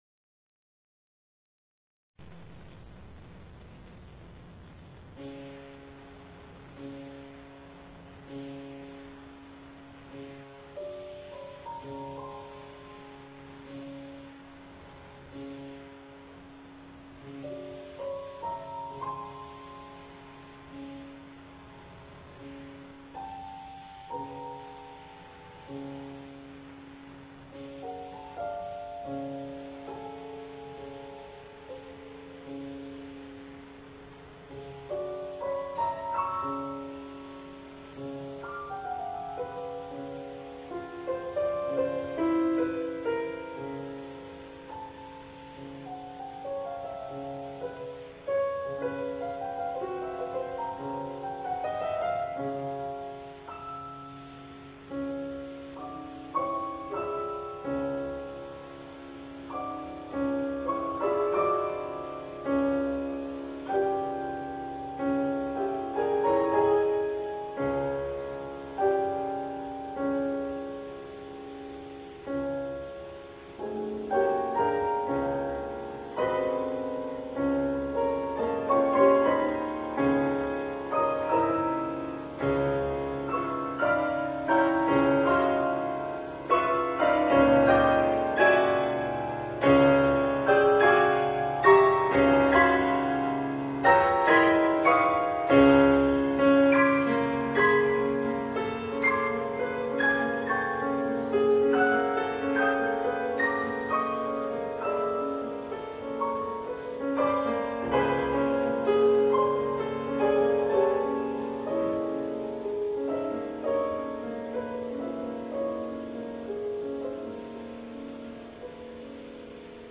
この演奏会は福井の「ユー・アイふくい」多目的ホールで行なわれました。
当日はトークを交えて楽しいコンサートとなりましたが、自編曲が私のピアノのテクニックを超えるハードだったため、体力的にも限界状態の演奏もあります。